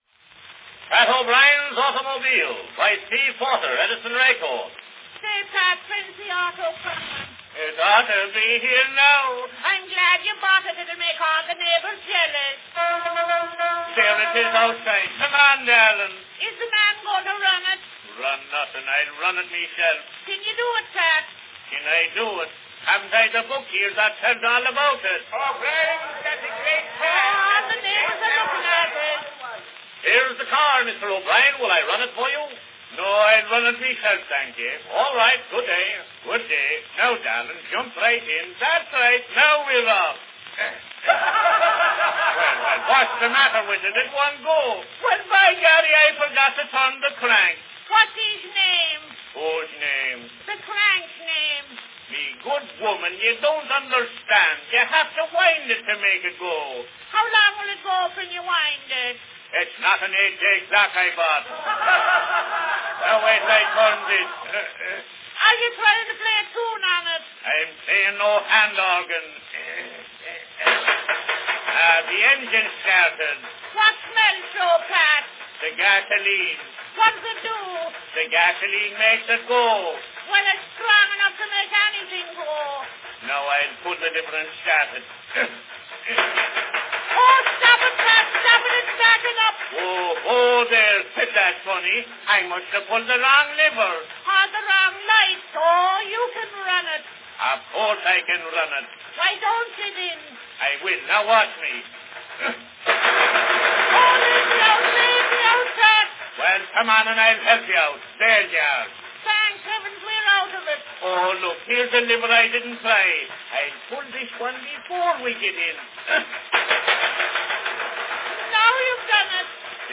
A vaudeville skit from 1908, Pat O'Brien's Automobile by Steve Porter.
Cylinder # 9970
Category Street scene
Performed by Steve Porter
Announcement "Pat O'Brien's Automobile, by Steve Porter.  Edison record."